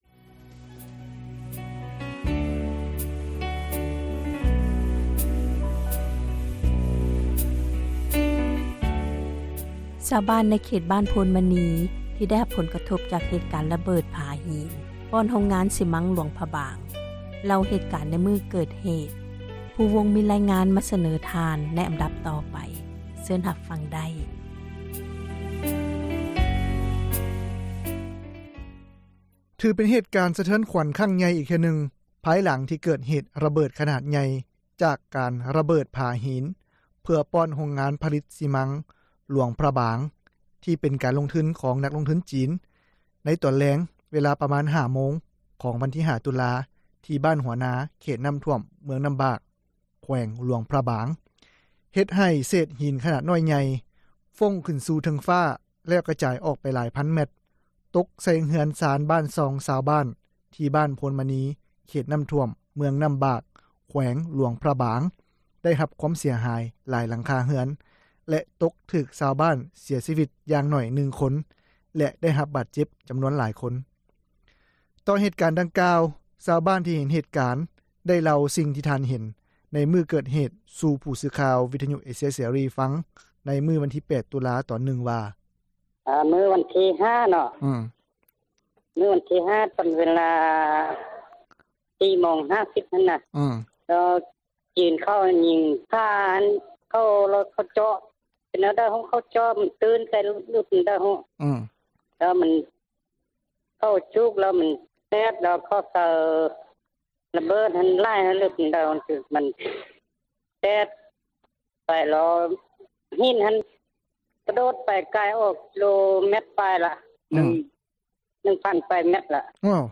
ຊາວບ້ານທ່ານນີ້ກໍຍັງບໍ່ສາມາດຮູ້ໄດ້ວ່າ ເກີດຍ້ອນຫຍັງກັນແທ້, ແຕ່ສິ່ງທີ່ ພໍຮັບຮູ້ກ່ອນເກີດເຫຕການ ໃນຄັ້ງນີ້ແລ້ວແມ່ນວ່າໂຮງງານຜລິຕຊີມັງ ແລະບ່ອນທີ່ມີການຣະເບີດຜາຫິນ ປ້ອນໂຮງງານ ແມ່ນມີເຈົ້າຂອງ 2 ເຈົ້າ ແລະ ເຈົ້າຂອງ 2 ເຈົ້າ ກໍມີຂໍ້ຂັດແຍ້ງກັນເອງ ມາກ່ອນໜ້ານີ້ ໄດ້ປະມານ 1 ສັປດາ ແລ້ວ. ສຽງPV2: “ສຽງຊາວບ້ານຜູ້ທີ 1”
ສຽງPV3: “ສຽງຊາວບ້ານຜູ້ທີ 2”
ສຽງPV4: “ສຽງເຈົ້າໜ້າທີ່ເມືອງນ້ຳບາກ”